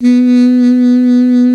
55AF-SAX04-B.wav